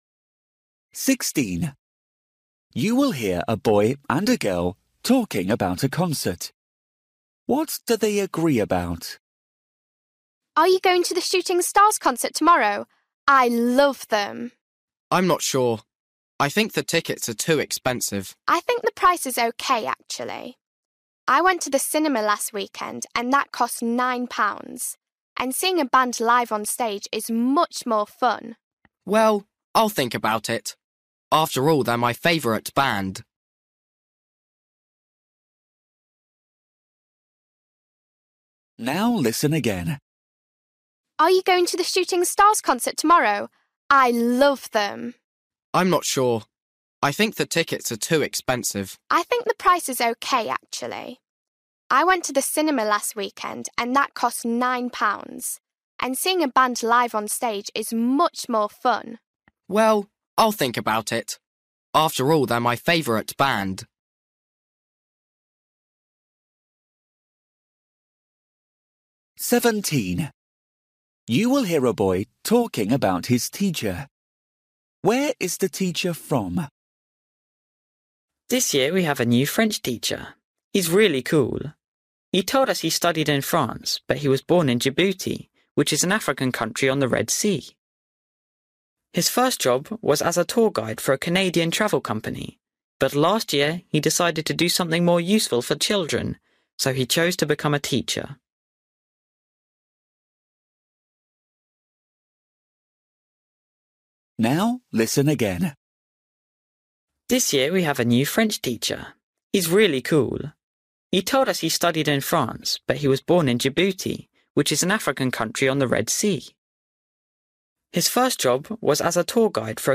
Listening: everyday short conversations
16   You will hear a boy and a girl talking about a concert. What do they agree about?
19   You will hear two friends planning the weekend. What are they going to do?